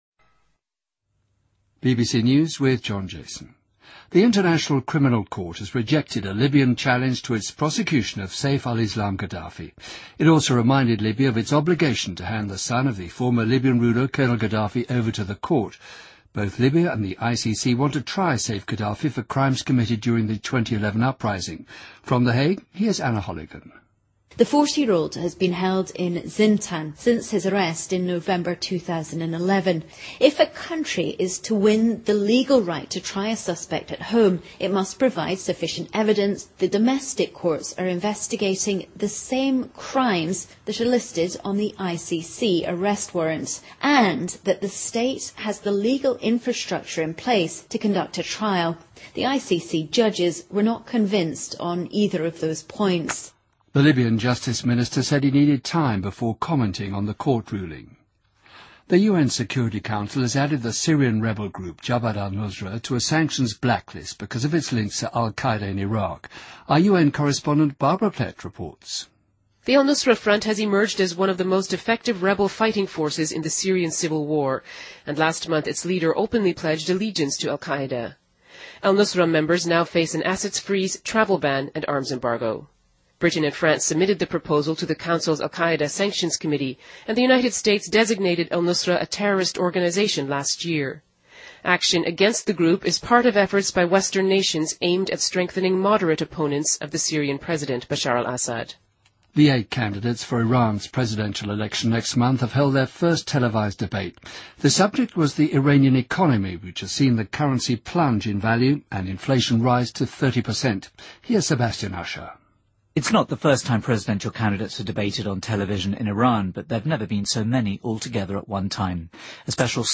BBC news,2013-06-01